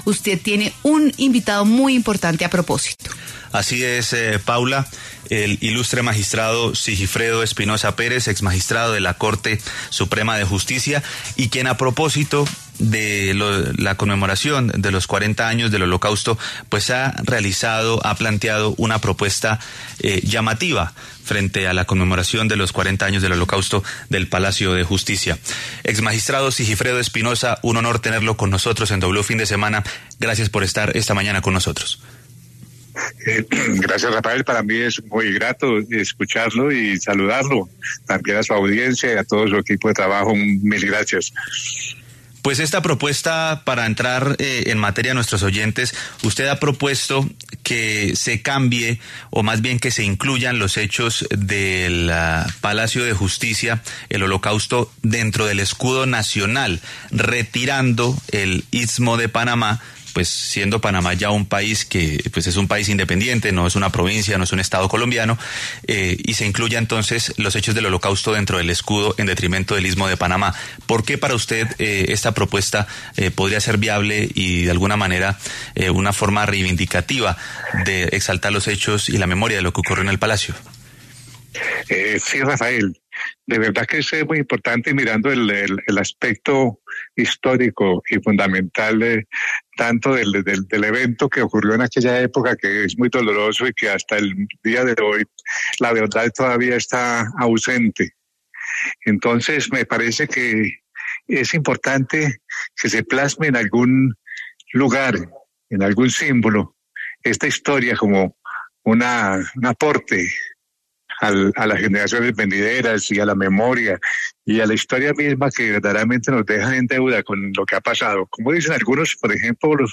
En diálogo con W Fin de Semana, el exmagistrado de la Corte Suprema Sigifredo Espinosa Pérez explicó su propuesta de cambiar el escudo de Colombia.